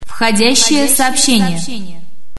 /96kbps) 16kbps 32kbps 48kbps Описание: Входящее сообщение ID 797266 Таги: mp3 Просмотрен 699 раз Скачан 45 раз Скопируй ссылку и скачай Fget-ом в течение 1-2 дней!